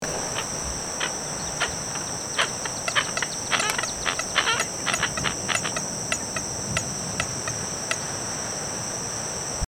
American Coot
The coot makes short croaks, clucks and cackles that sound like kuk-kuk-kuk. It will make these sounds for a variety of reasons, such as to attract potential mates and ward off predators.
american-coot-call.mp3